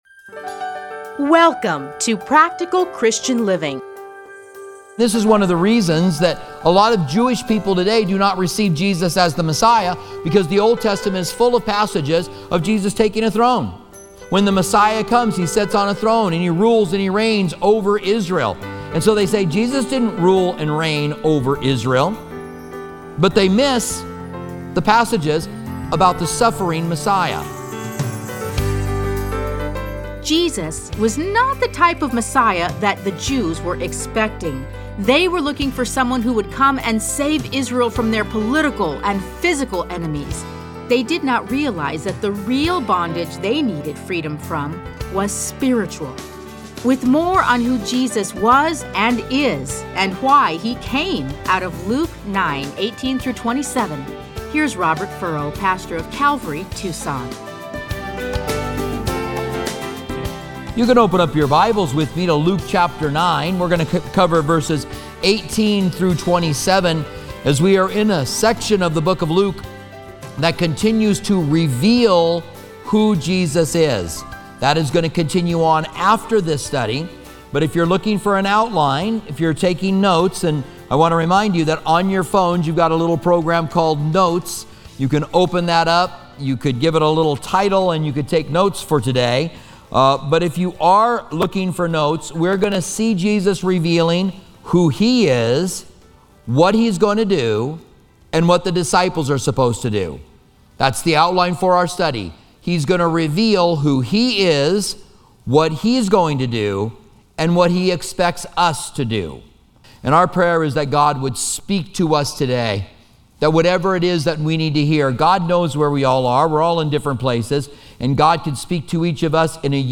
Listen to a teaching from Luke 9:18-27.